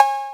808cowb.wav